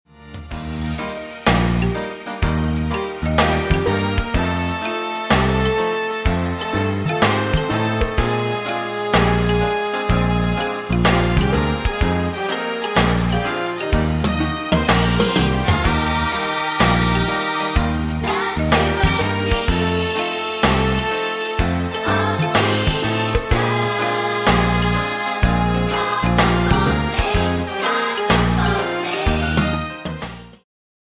充滿動感和時代感
有伴奏音樂版本
伴奏音樂